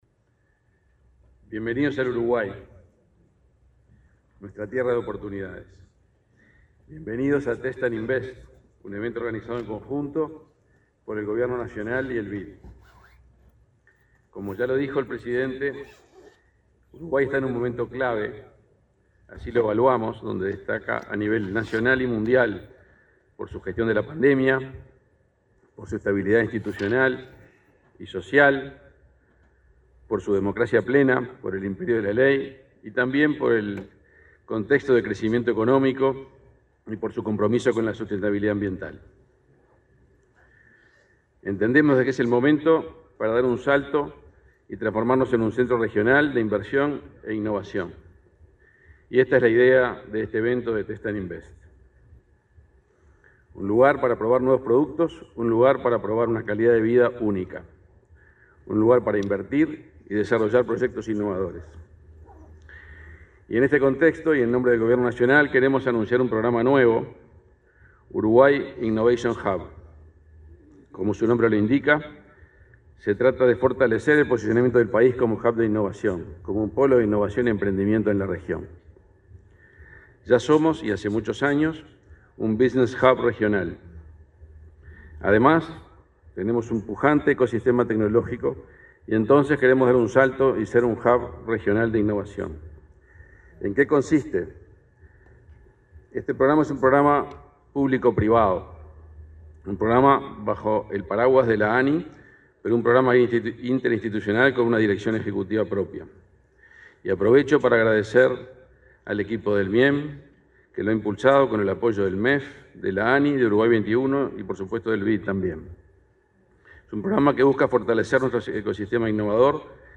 El ministro de Industria, Energía y Minería, Omar Paganini, disertó este jueves 3 en Punta del Este, en la apertura del evento Test & Invest Uruguay